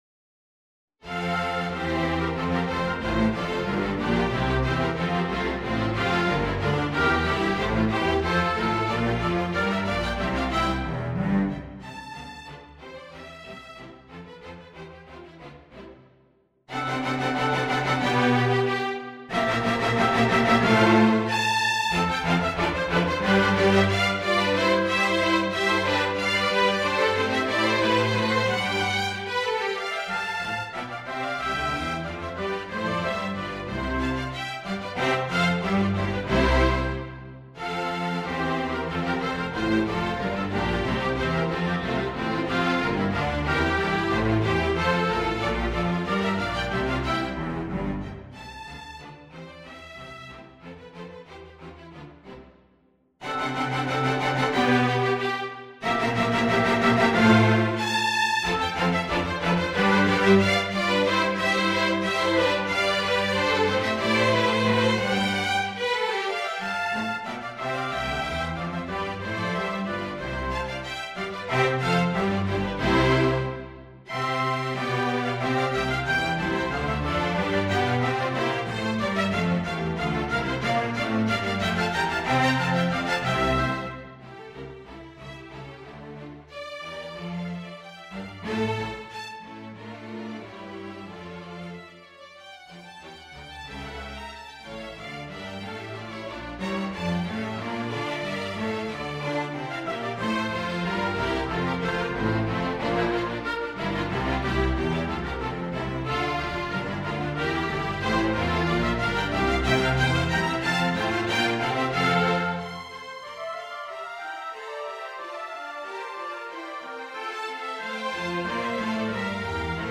Orchestration
2 Oboes [optional], 2 Horns [optional]
Violin 1, Violin 2, Viola (or Violin 3), Cello, Bass